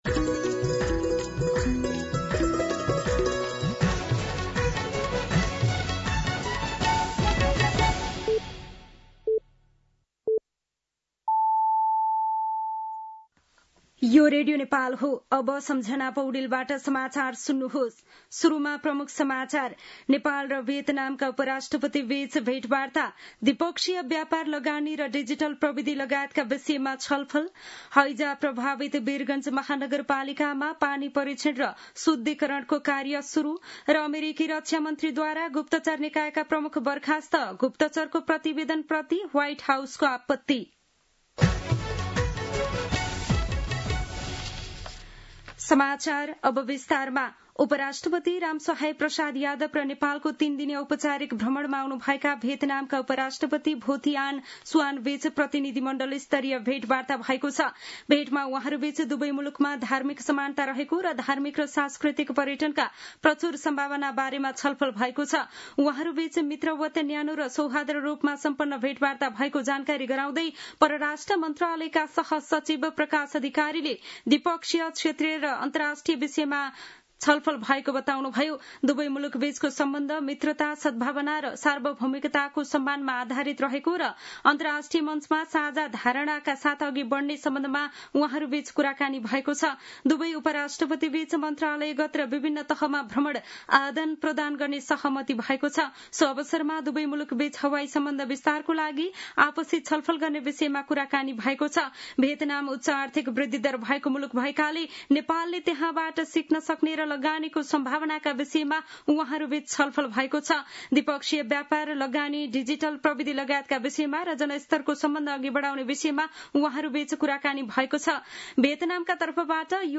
दिउँसो ३ बजेको नेपाली समाचार : ८ भदौ , २०८२